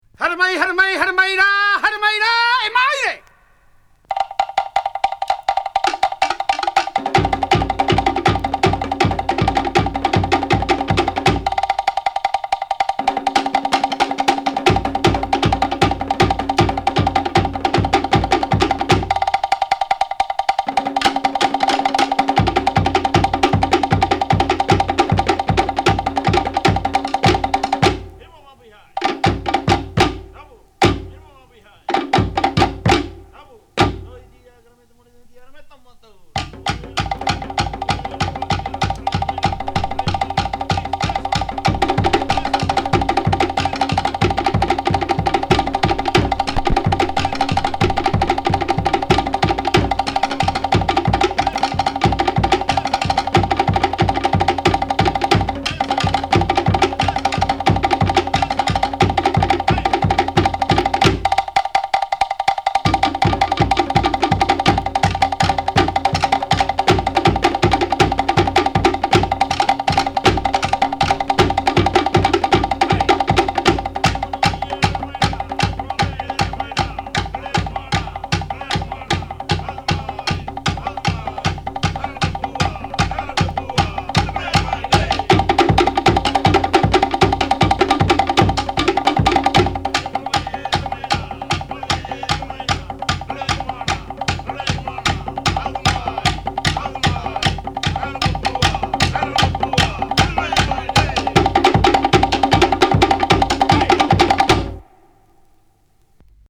tahitian-drumming.mp3